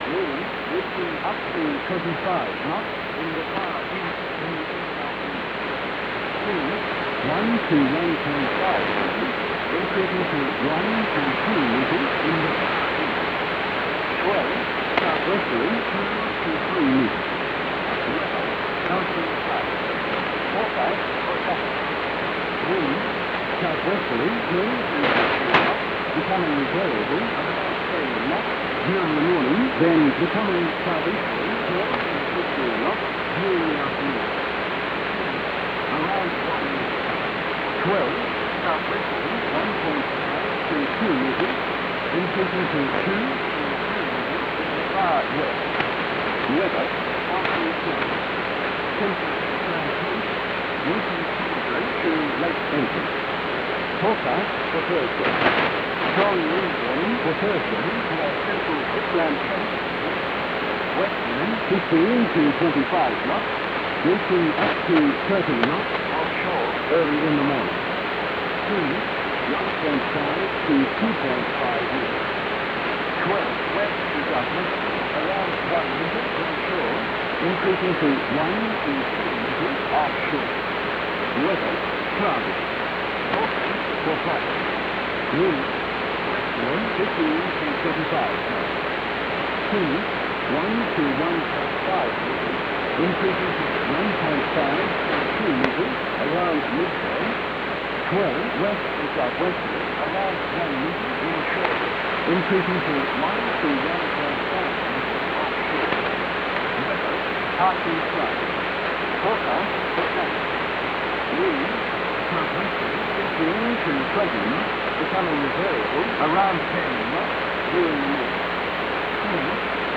VMC Charleville (Queensland) received 51 to 55, moderate QSB.
OM giving weather for Tasmania.